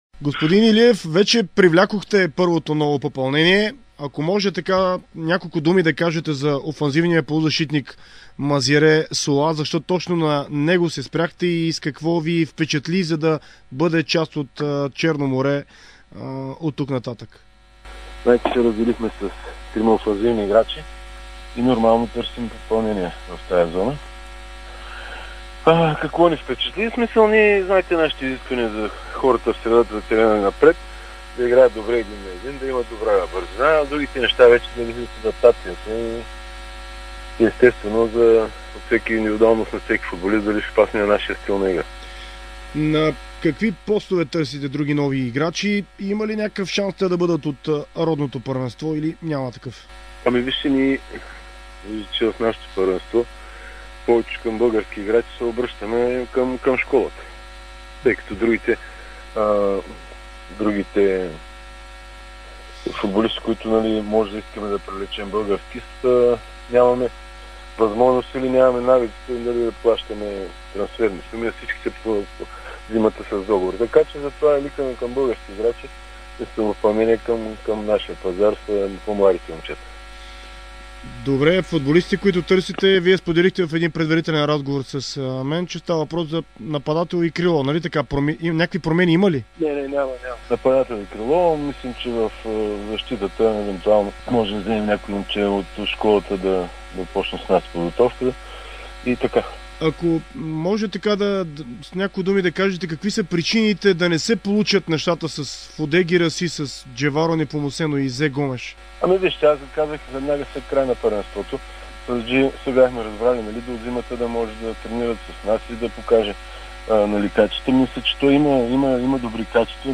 В интервю за Дарик радио и dsport той сподели, че от Ботев Пд не са постъпили коректно спрямо Черно море за Сердюк, тъй като веднага след мача между двата тима са се свързали първо с него.